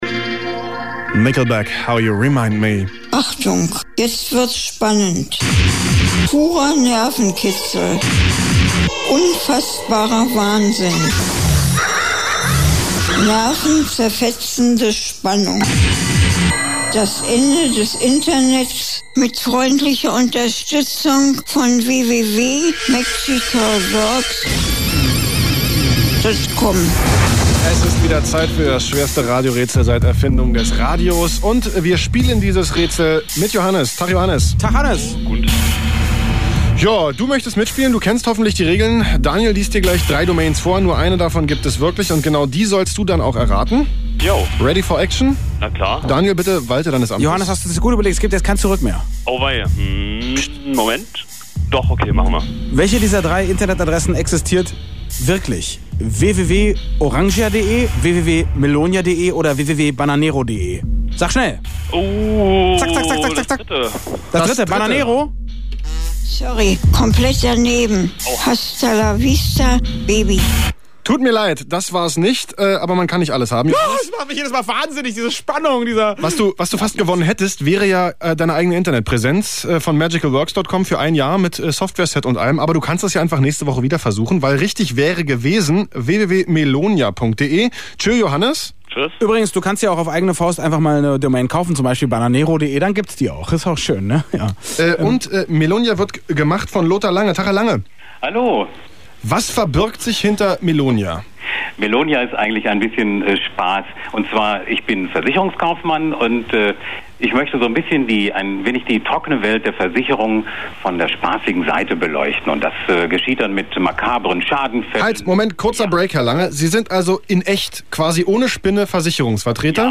Sie wurde sogar einmal zu einem Thema bei einer Radio-Show des Berliner Senders RADIO EINS.
melonia-radio.mp3